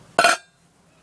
罐子 " 放置罐子 02
描述：通过将罐放在混凝土表面上而产生的声音。录音设备：第4代iPod touch，使用media.io转换。